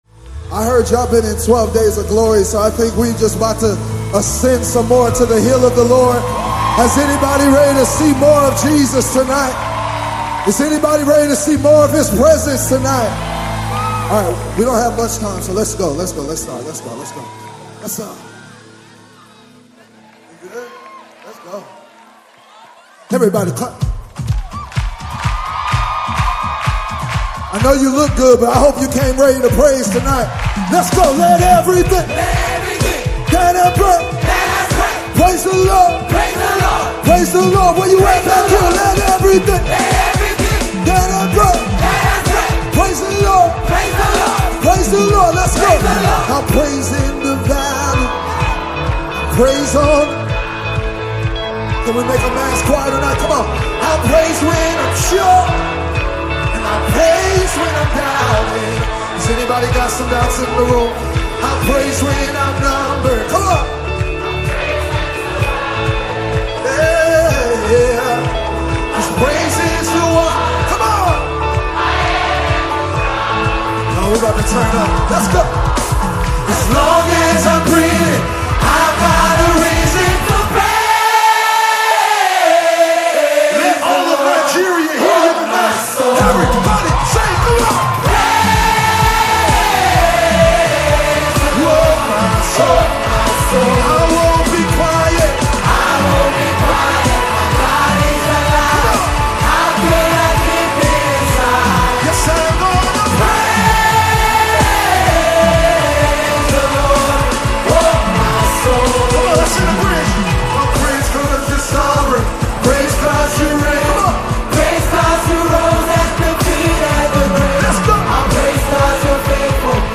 Contemporary Christian music singer